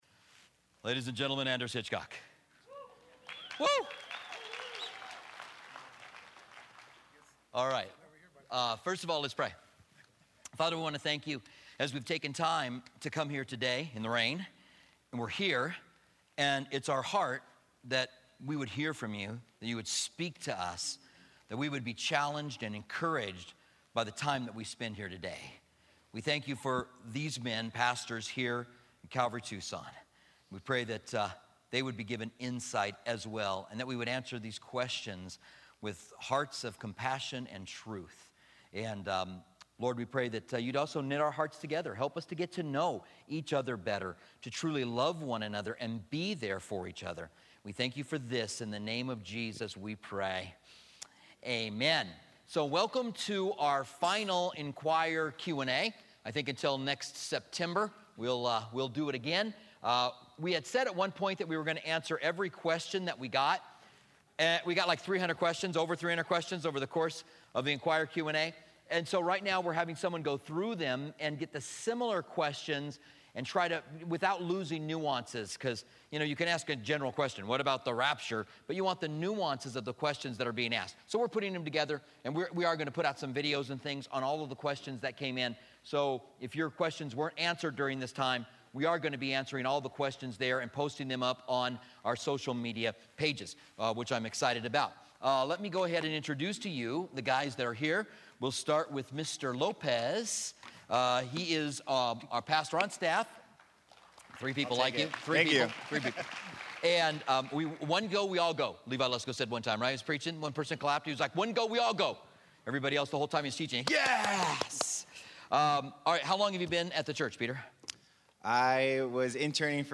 Inquire Q&A - Session 5 - Staff Panel B - East Campus Sep 28, 2016 · Multiple Listen to a pannel of our pastors give their answers for questions submitted to the Inquire Q&A series.